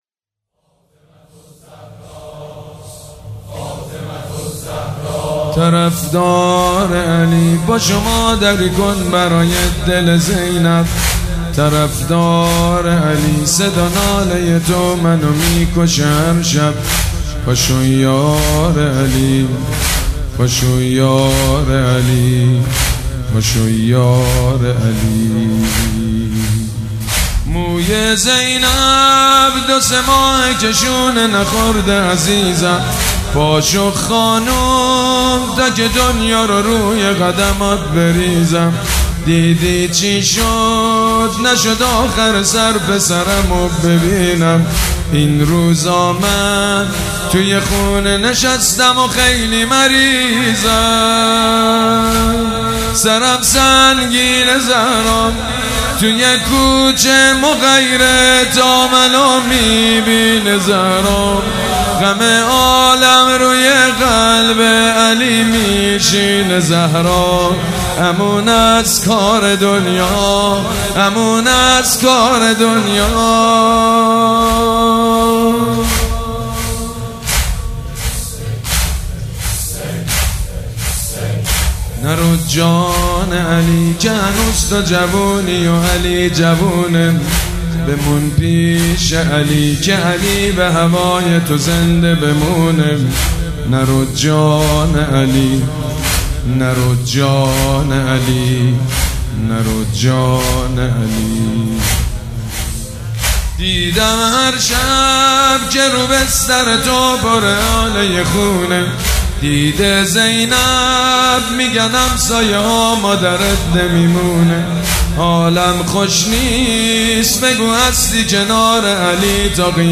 مداح
حاج سید مجید بنی فاطمه
مراسم عزاداری شب شهادت حضرت زهرا (س)